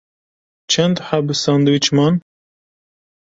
Pronunciado como (IPA)
/ħɛb/